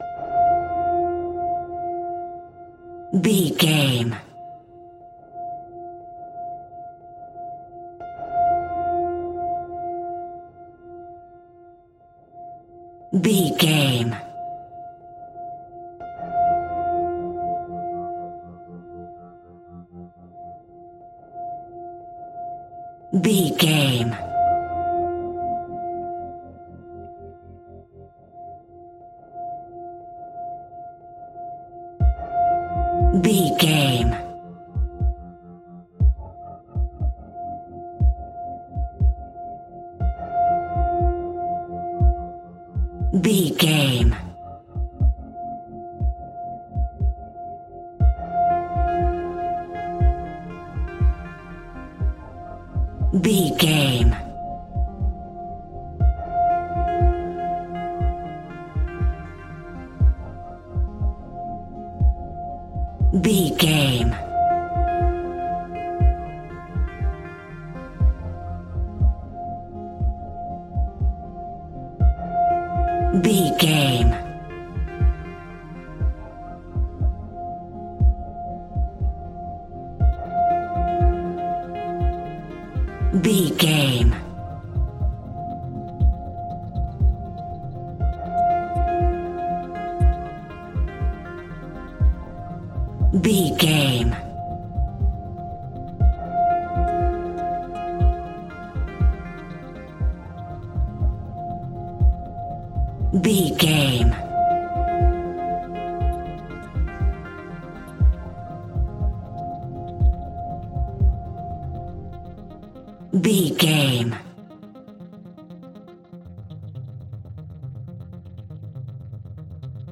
Thriller
Aeolian/Minor
scary
ominous
dark
suspense
haunting
eerie
piano
percussion
mysterious